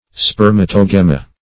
Search Result for " spermatogemma" : The Collaborative International Dictionary of English v.0.48: Spermatogemma \Sper`ma*to*gem"ma\, n. [NL.